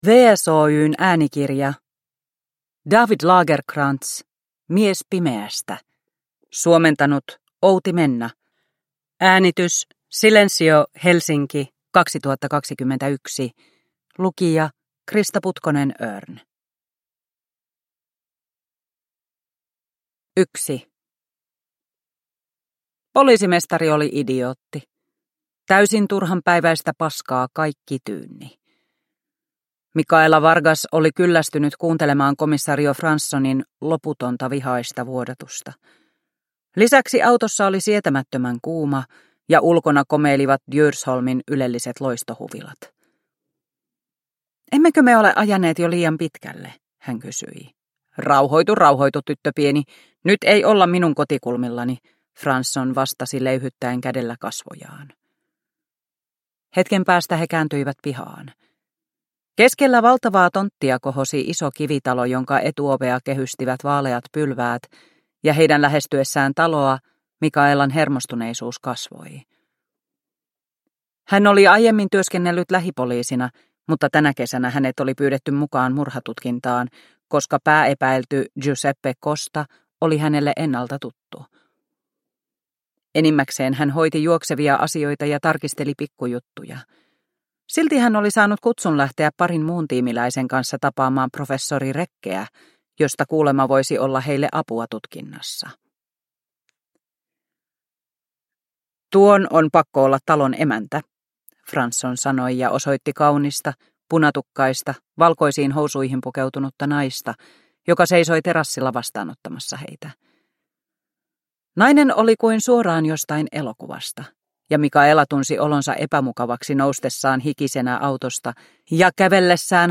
Mies pimeästä – Ljudbok – Laddas ner